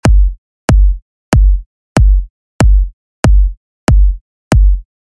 オーディオファイルの直張りと、Arcareでの再生音、それを逆位相でぶつけた音を比較しました。
●DAW直張り
kick_org.mp3